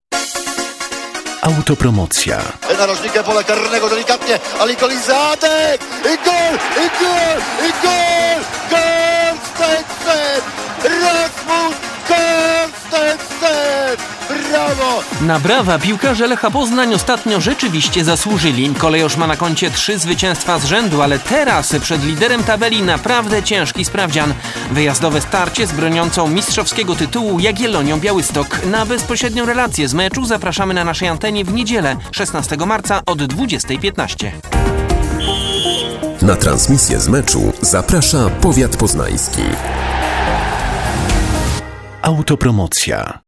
Radiowe zapowiedzi meczów